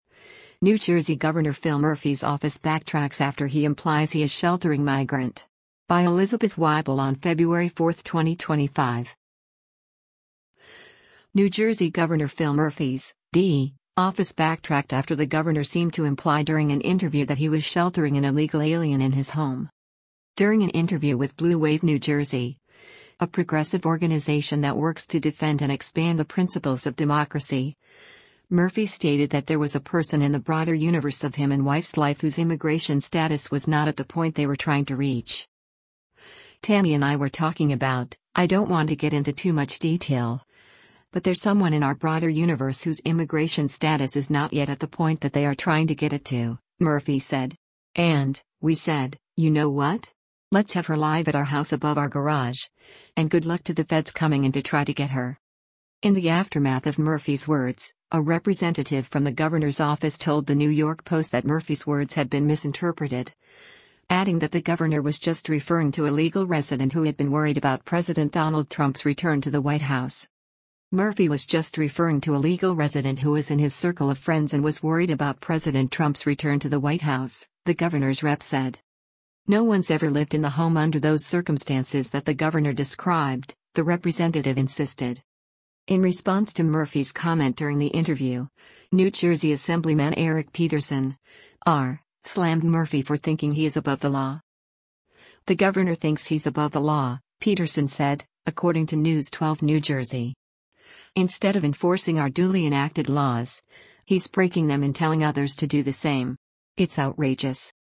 New Jersey Gov. Phil Murphy’s (D) office backtracked after the governor seemed to imply during an interview that he was sheltering an illegal alien in his home.